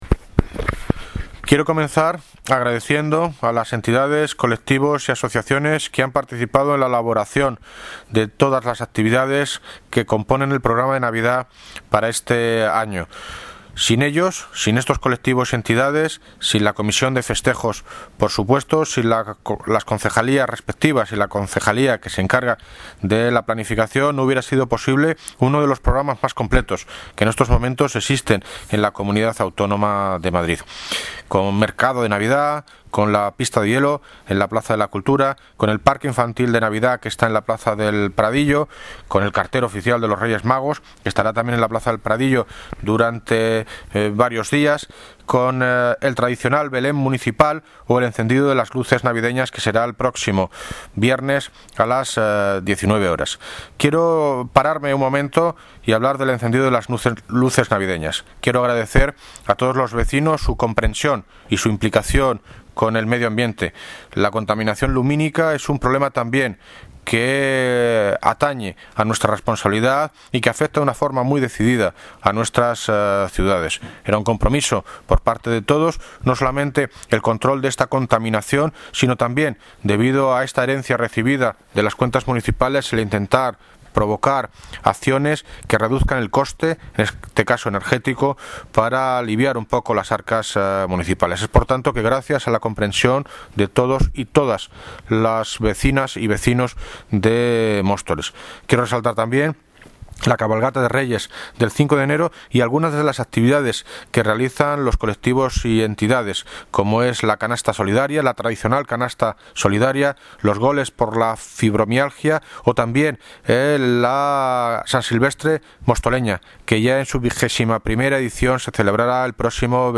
Audio - David Lucas (Alcalde de Móstoles) Sobre programación Navidad